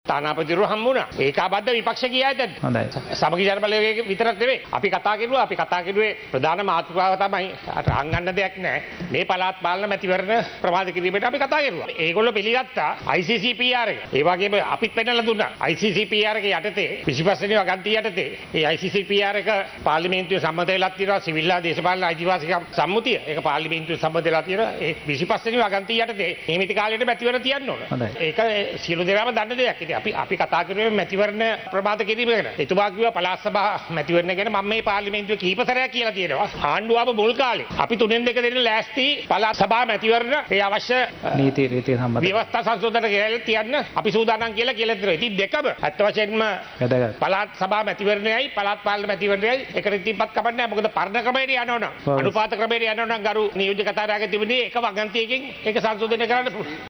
පළාත් පාලන මැතිවරණය නොපැවැත්වීම සම්බන්ධයෙන් සමගි ජනබලවේගය පක්ෂය ඇතු`ඵ විපක්ෂයේ බොහෝ පිරිසක් තානාපතිවරුන් මුණ ගැසී අදහස් ප්‍රකාශ කළ බවටයි සමගි ජනබලවේගය පක්ෂයේ පාර්ලිමේන්තු මන්ත්‍රී ලක්ෂ්මන් කිරිඇල්ල මහතා අද පාර්ලිමේන්තුවේදී කියා සිටියේ.